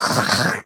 Minecraft Version Minecraft Version snapshot Latest Release | Latest Snapshot snapshot / assets / minecraft / sounds / mob / rabbit / attack3.ogg Compare With Compare With Latest Release | Latest Snapshot